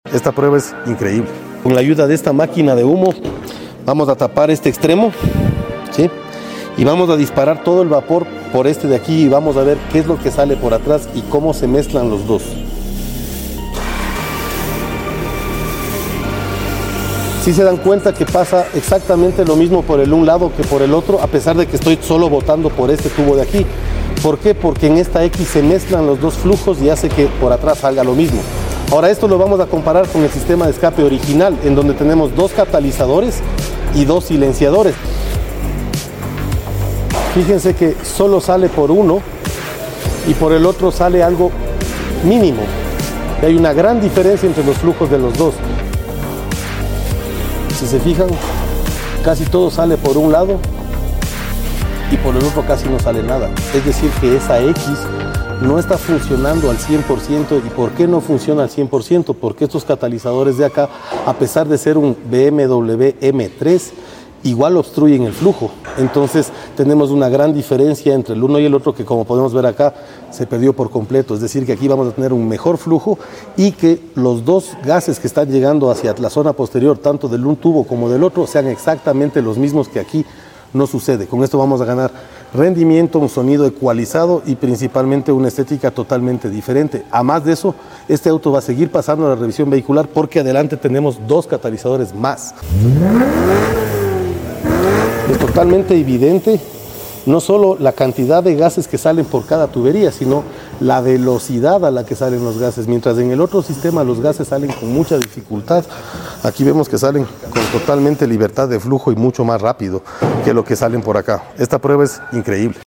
¡Demos un vistazo a esta prueba de la mezcla de gases en la X-Pipe instalada en este BMW M3! 💨✨ En AutoX, transformamos el rendimiento de este BMW mejorando el sistema de escape original con nuestro diseño a la medida.